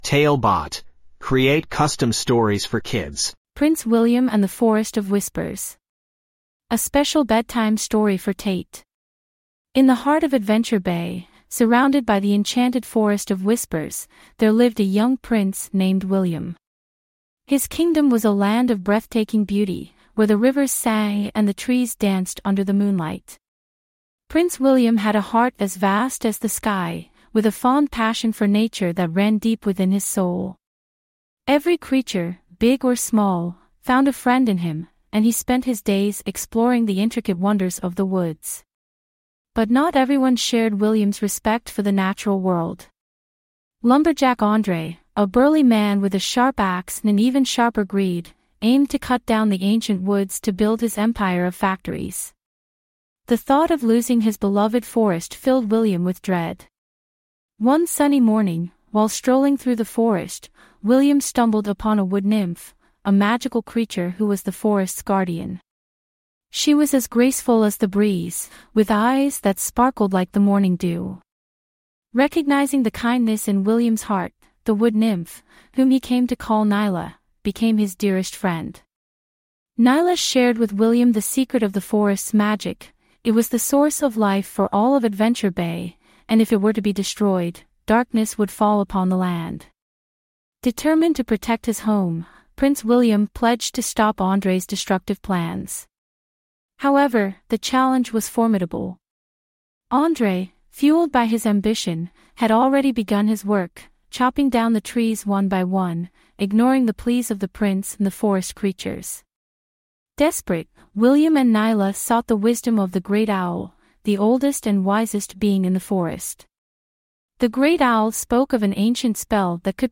5 Minute Bedtime Stories